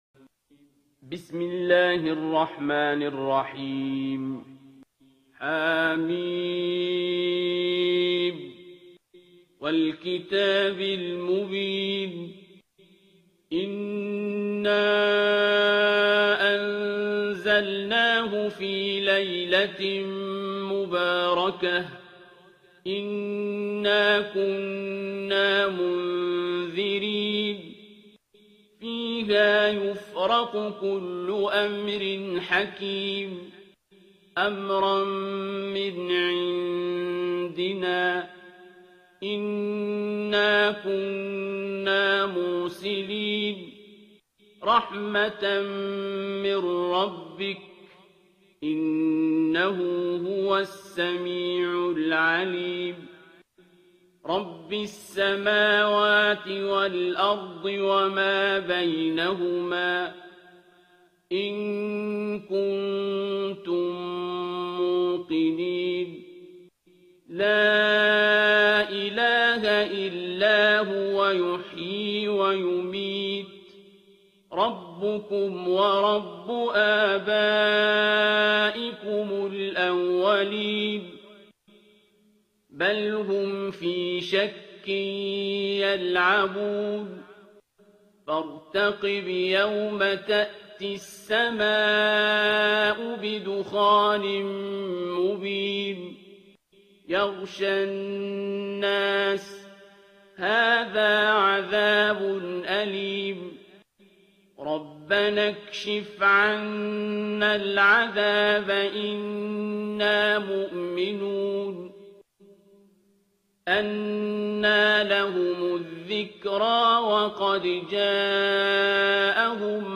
ترتیل سوره دخان با صدای عبدالباسط عبدالصمد
044-Abdul-Basit-Surah-Ad-Dukhan.mp3